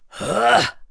Roman-Vox_Casting2.wav